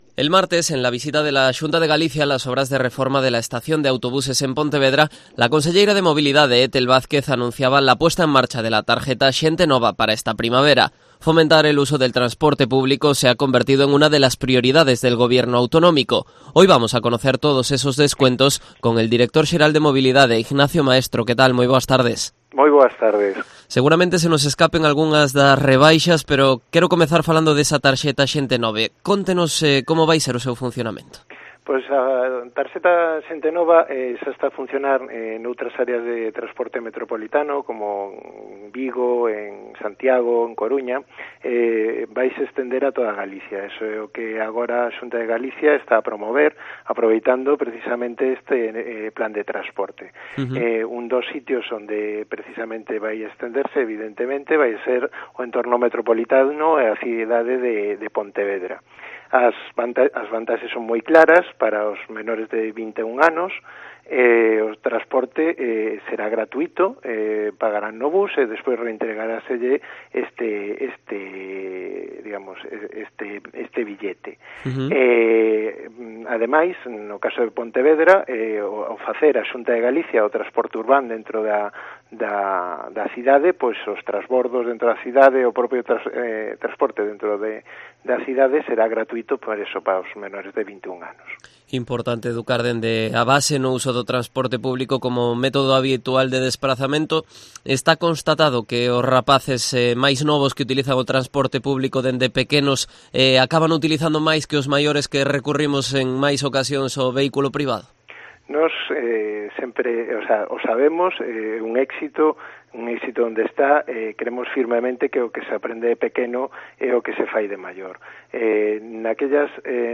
Entrevista del director xeral de Mobilidade, Ignacio Maestro, en Cope Pontevedra